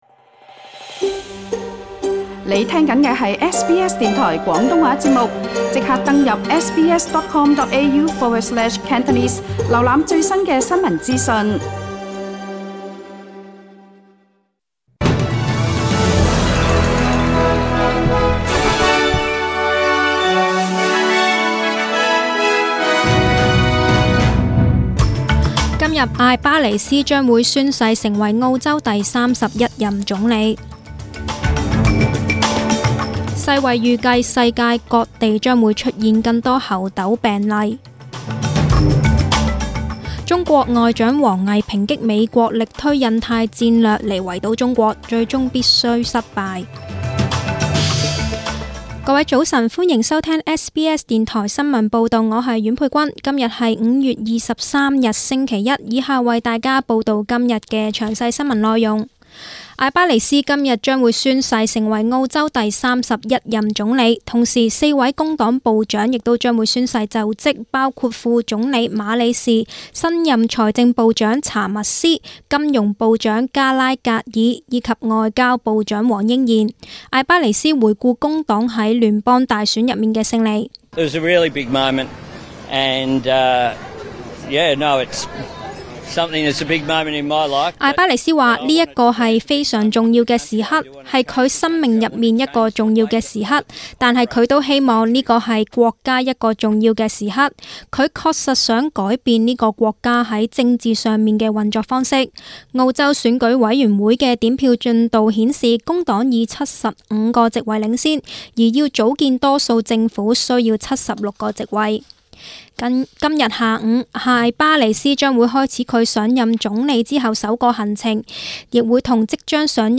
SBS 廣東話節目中文新聞 Source: SBS Cantonese